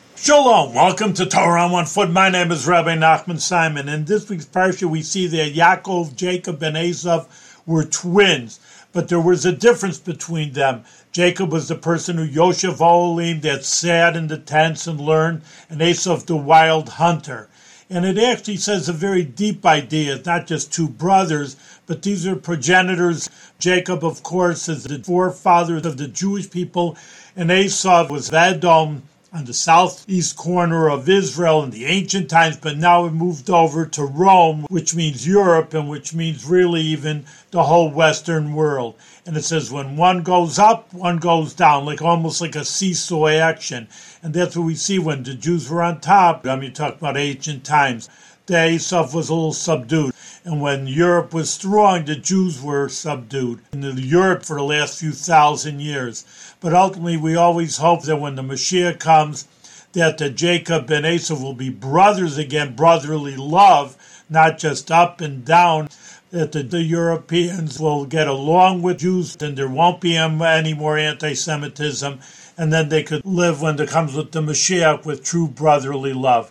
One-minute audio lessons on special points from weekly Torah readings in the Book of Genesis.